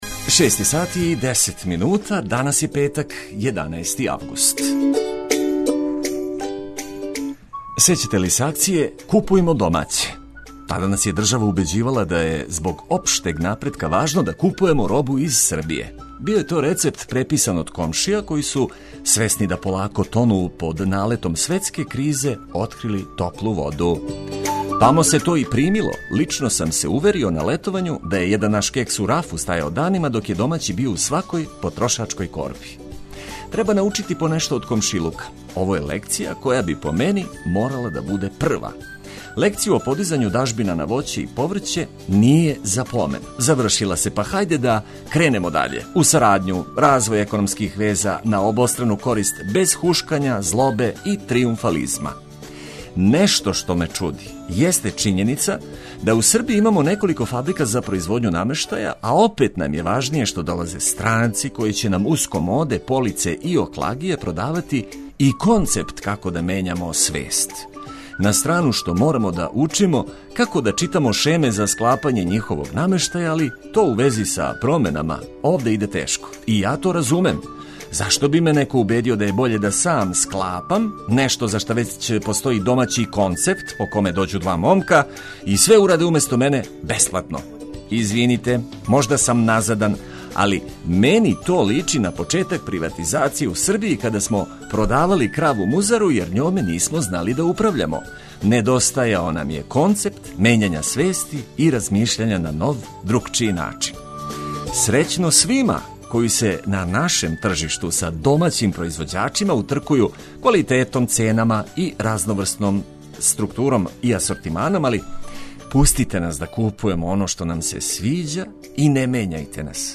Јутро бојимо ведрином, важним вестима, лепим причама и летњим хитовима.